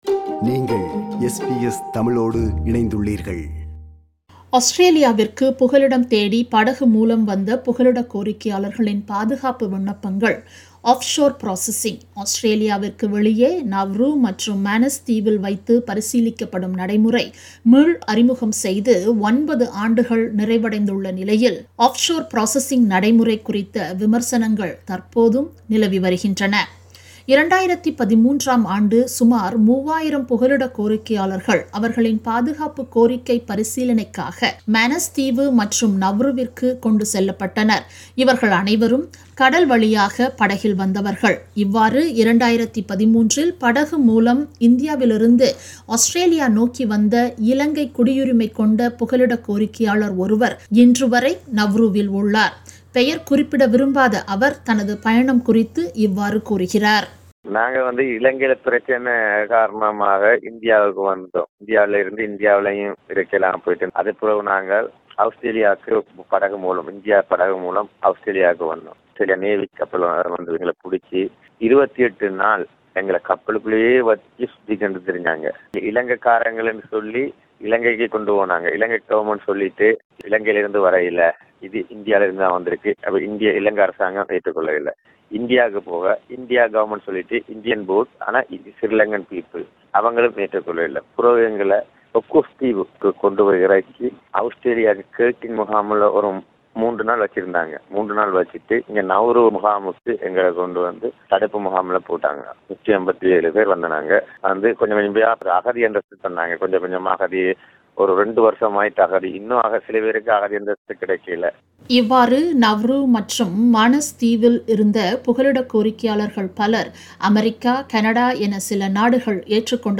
Offshore Processing நடைமுறையின் நோக்கம் நிறைவேறியதா? இந்த நடைமுறையினை தொடர்ந்து கடைபிடிப்பது அவசியமா? போன்ற விடயங்களை அலசுகிறது இந்த விவரணம்.